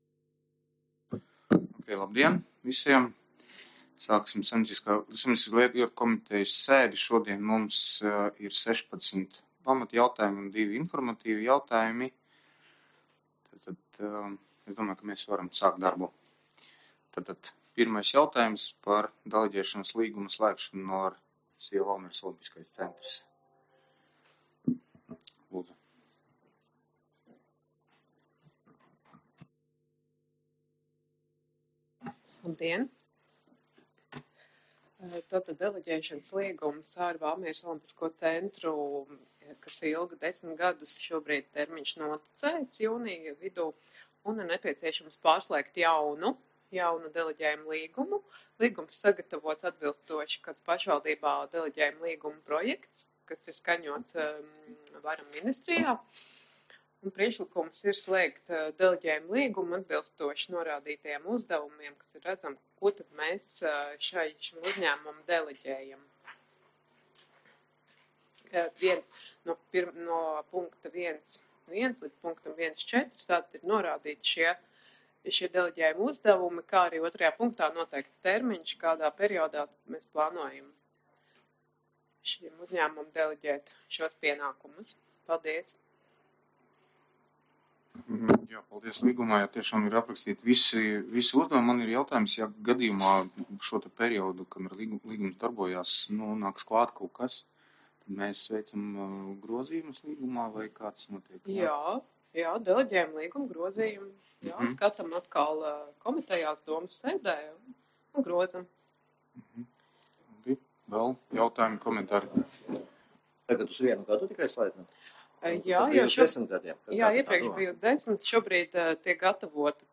Sēdes audioieraksts